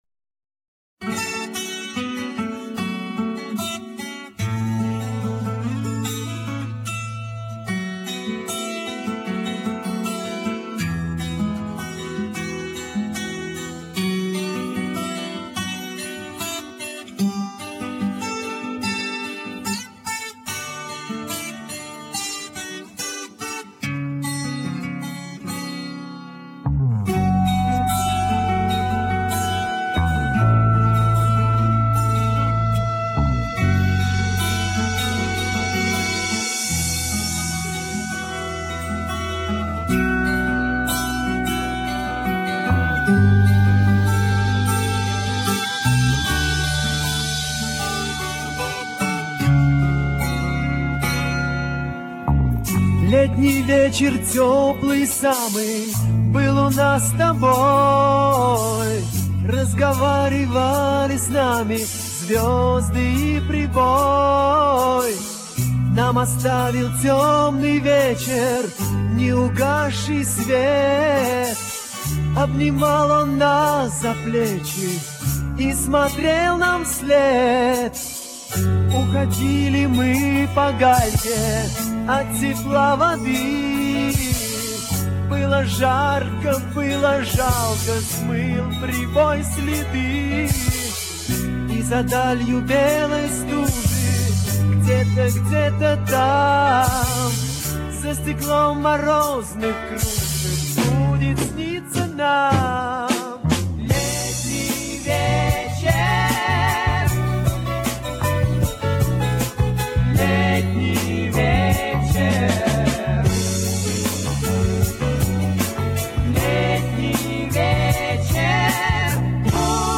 аудиоструя 20 Kbs, моно